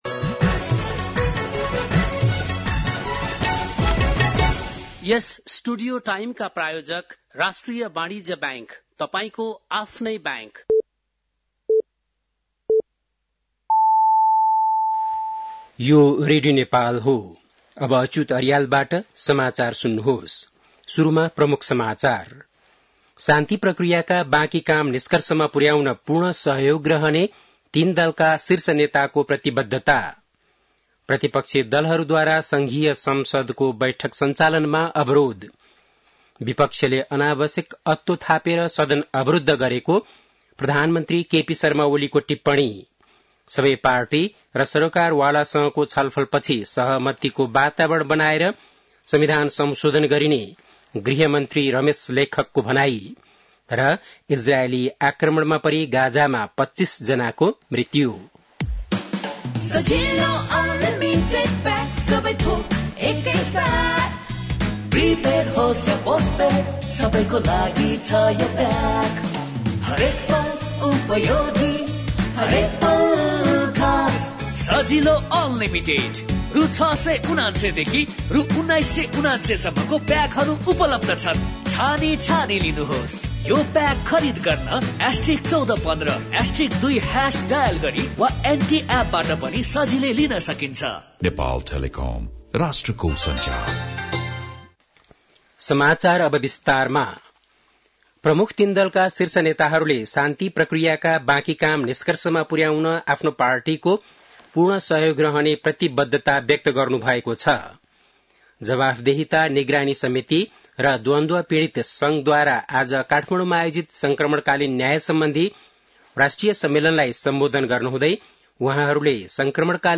बेलुकी ७ बजेको नेपाली समाचार : १४ चैत , २०८१
7-pm-nepali-news-12-14.mp3